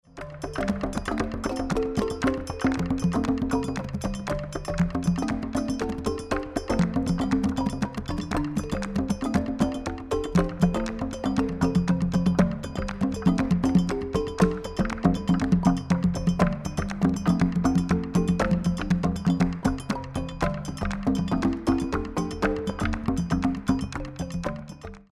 Cd of African rhythms
9 percussion tracks  - some vocals
Based on traditional Gyil melody